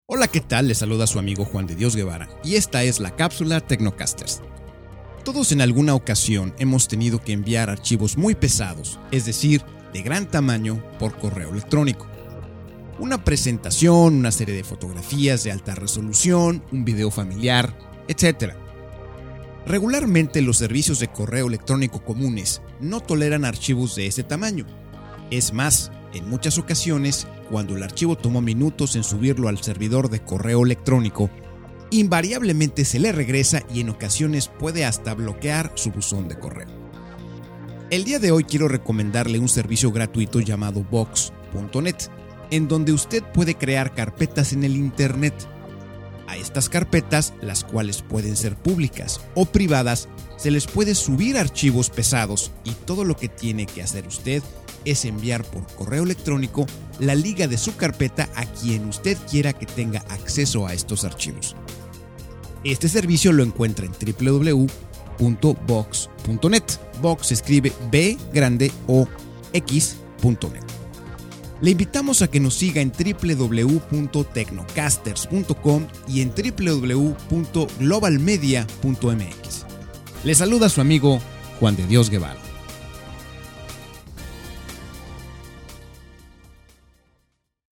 Capsula para Transmision en Radio.